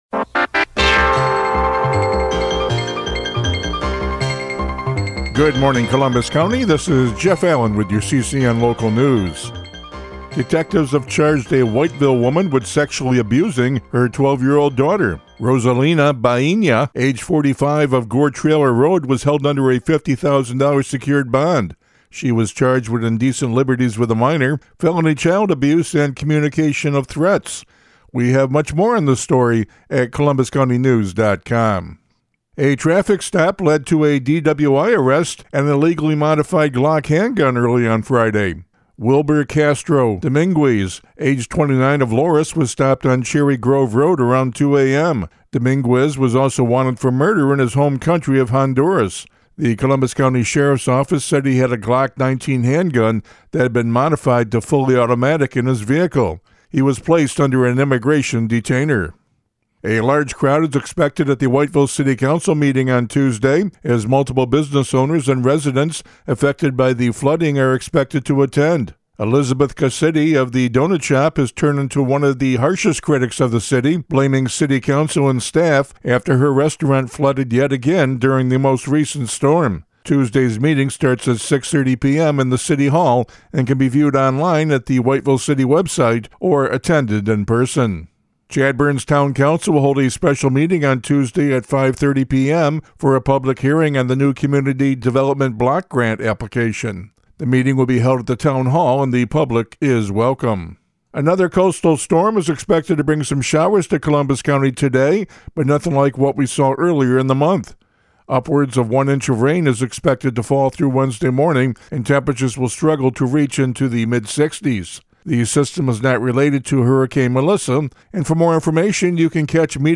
CCN Radio News — Morning Report for October 27, 2025